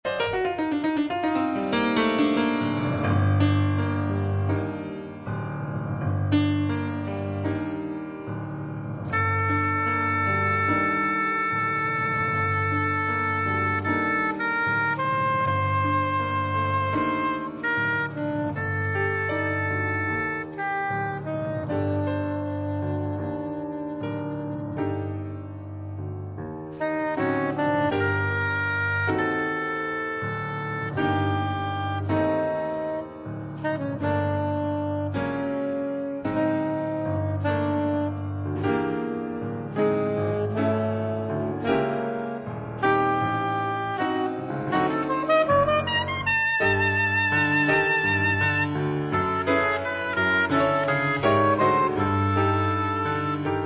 piano
soprano saxophone